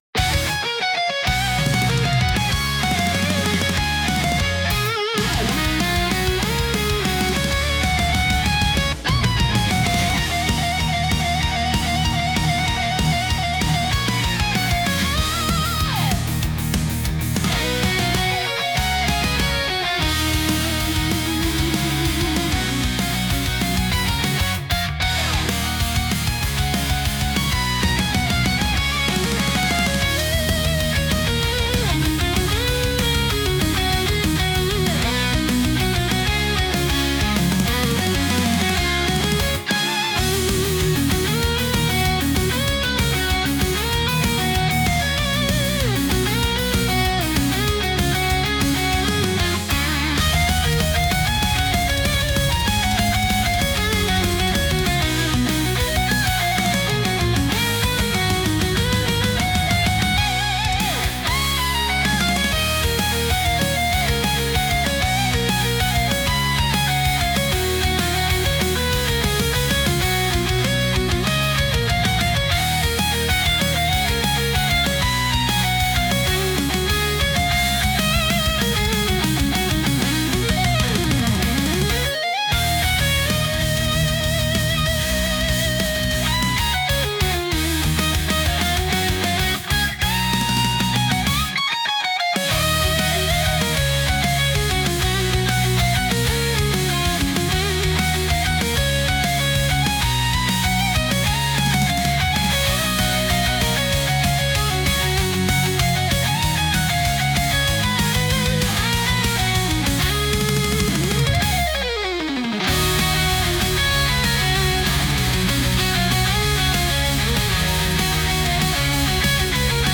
負けられない戦いに臨むためのBGM